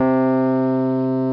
E Piano Lo Sound Effect
e-piano-lo.mp3